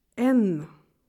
En cliquant sur le symbole, vous entendrez le nom de la lettre.
lettre-n.ogg